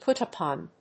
アクセントpút‐upòn
音節pút-upòn